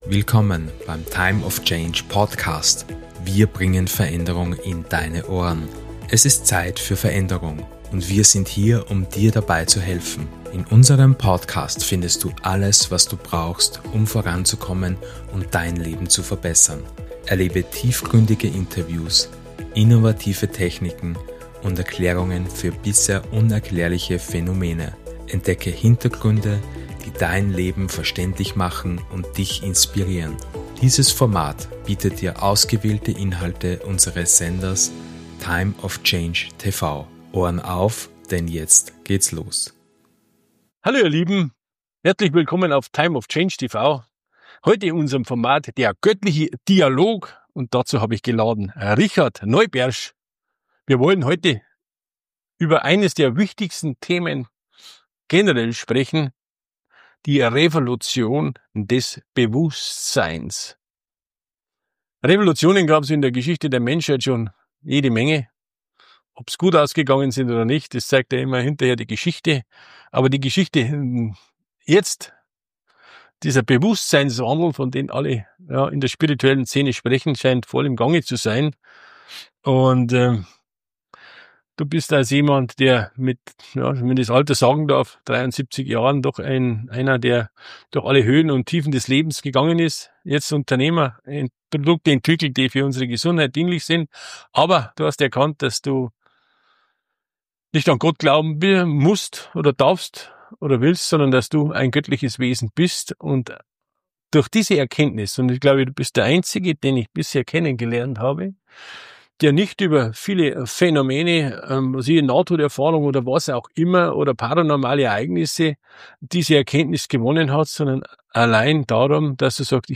Tauche ein in den sechsten Teil unserer faszinierenden Interviewreihe "Göttlicher Dialog"